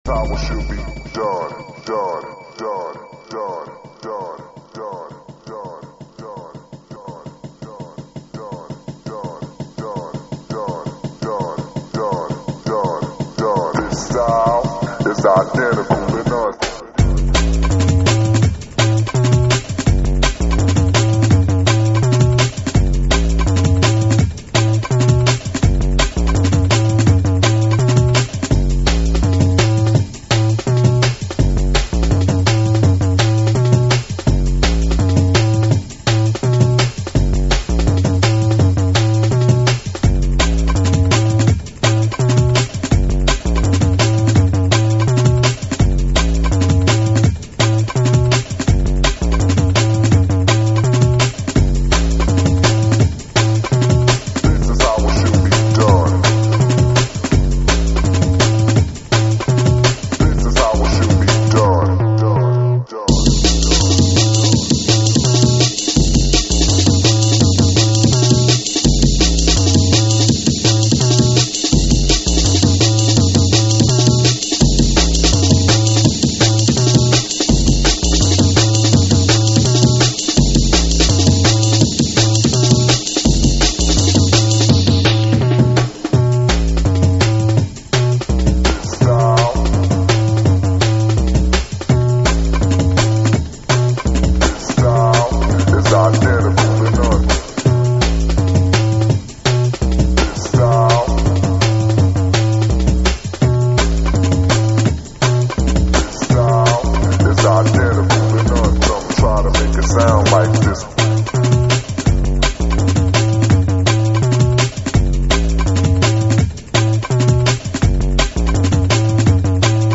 Drum&Bass, Dubstep